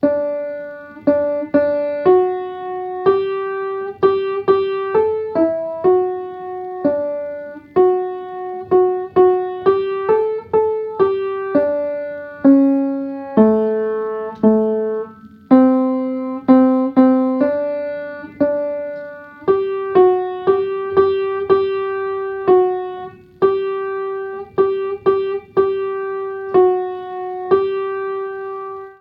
Hymn
See note above   Piano recordings
Soprano